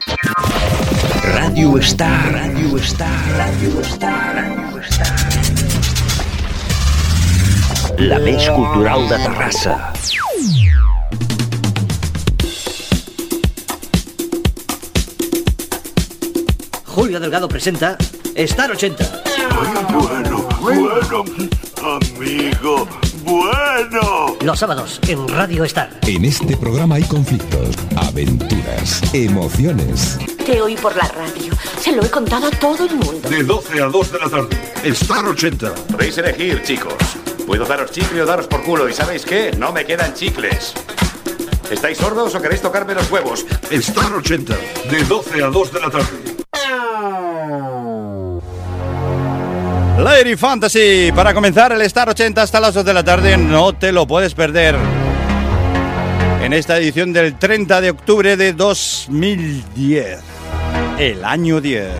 Indicatiu, careta del programa, data i tema musical.
Musical
FM